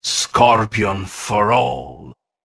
ok, Now its voice content has also been completed